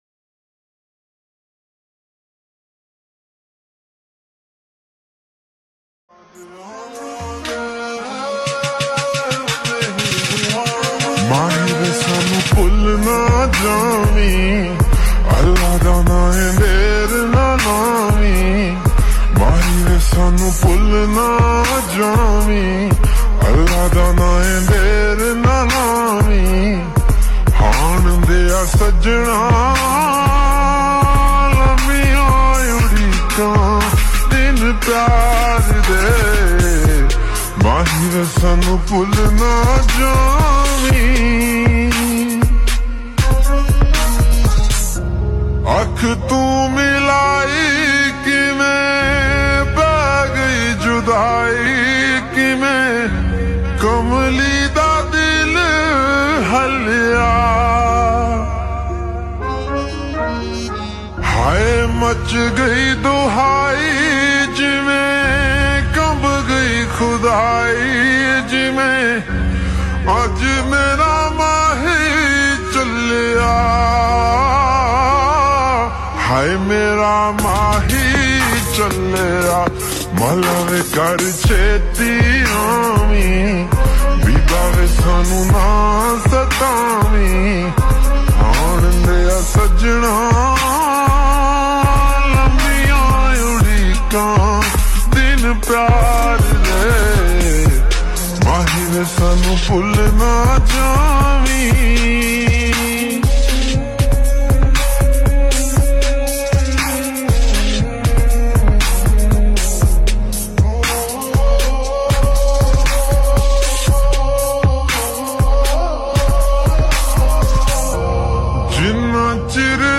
SLOW REVERB SONG